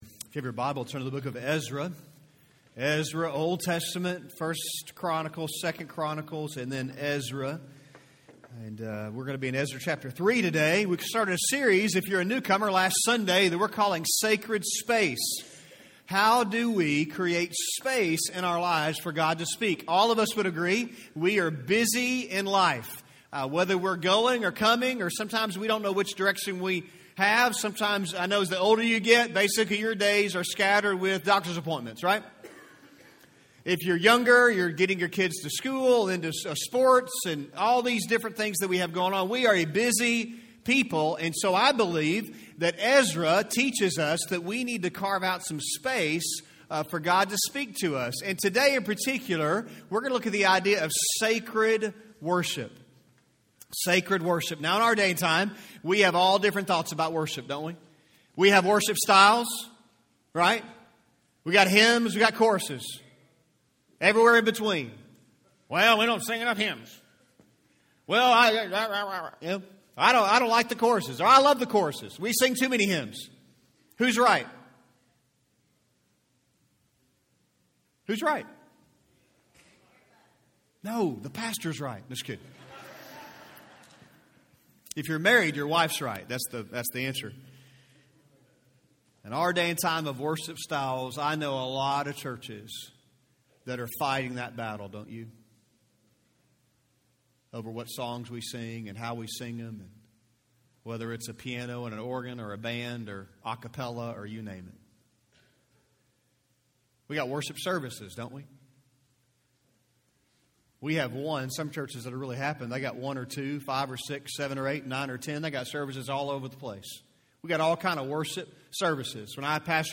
A message from the series "Sacred Space."